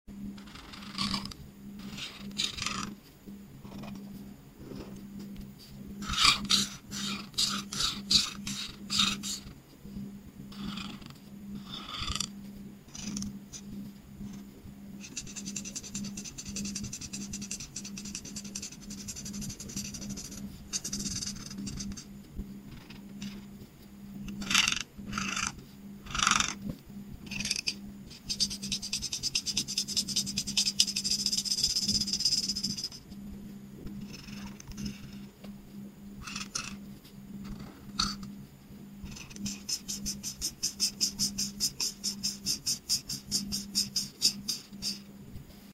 ASMR Colors: Coloring a Random sound effects free download
Watch every smooth glide of color and enjoy the relaxing sounds that make ASMR art so addictive.